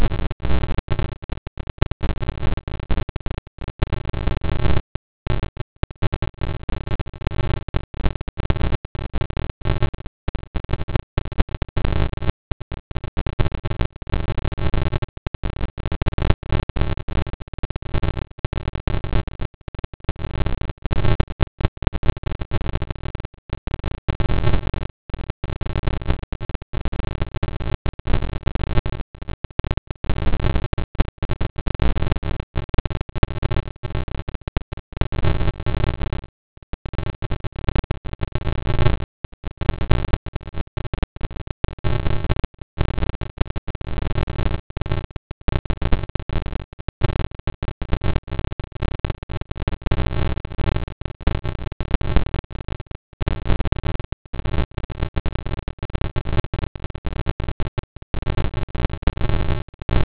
pulsar sound